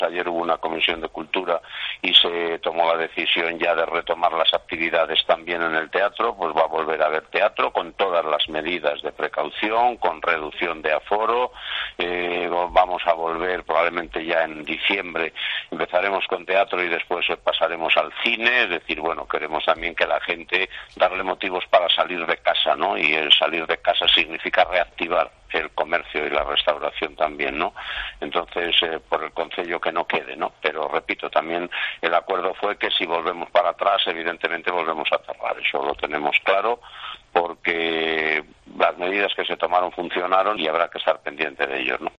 Declaraciones del alcalde de O Barco, Alfredo García, sobre los espectáculos de teatro y cine